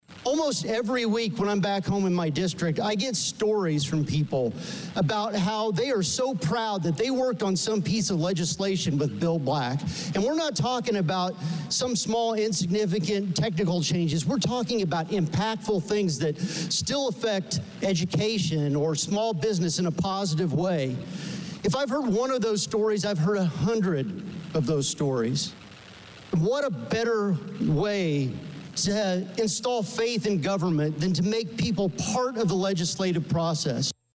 State Representative Mike Marron (R-Fithian) sponsored the resolution and spoke about his mentor on the House floor before its adoption.
COMMENTS FROM STATE REP MARRON: